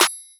MB Clap (8).wav